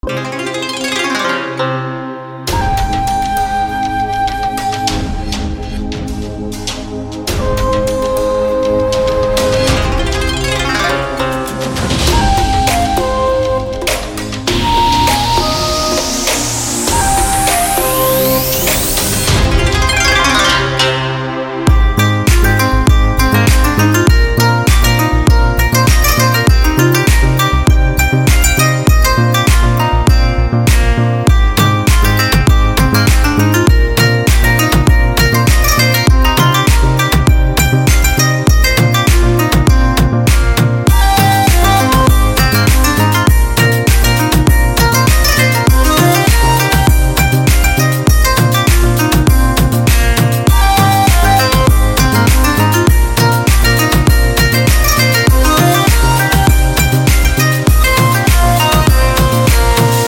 我们还包括28个原始录制的吉他环，供您集成到有机人体元素中。